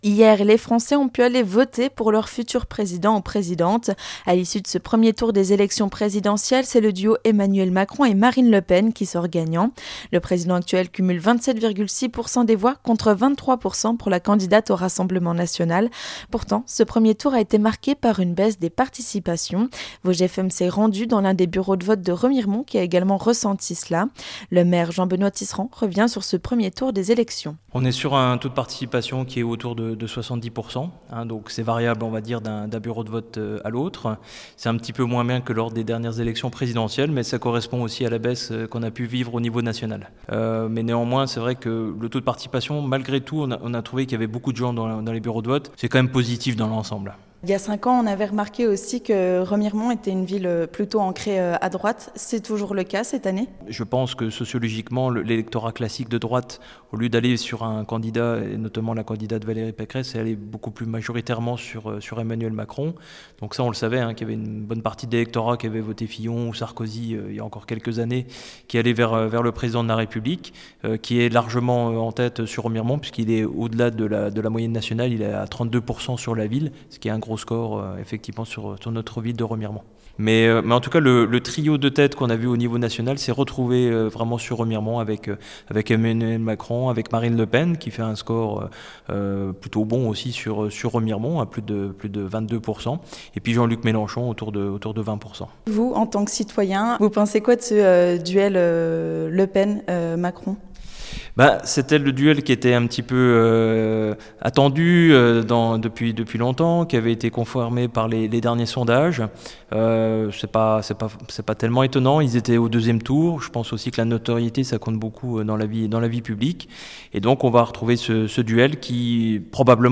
Ce duel donne un goût de déjà-vu qui déçoit les Romarimontains. Vosges FM est parti à leur rencontre à l'issu des résultats du premier tour. Jean-Benoît Tisserad, maire de Remiremont est également intervenu sur le taux de participation dans sa ville.